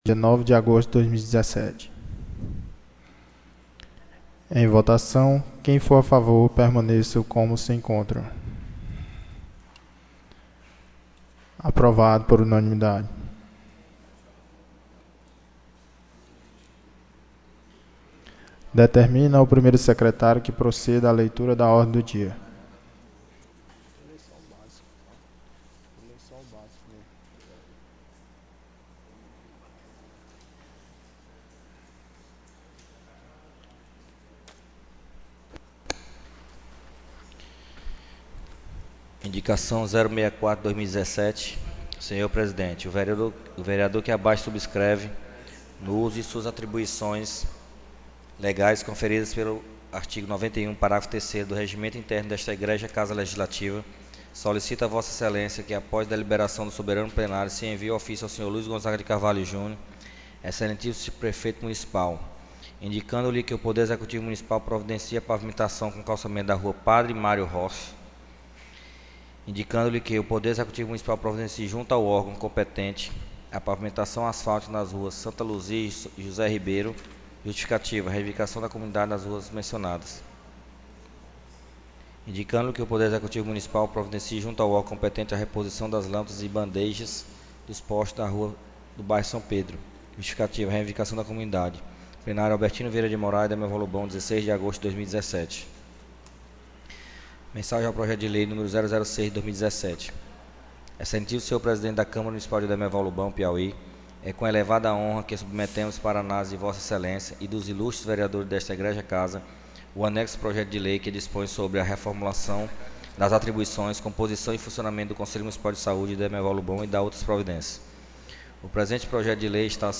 16ª SESSÃO ORDINÁRIA 16/08/2017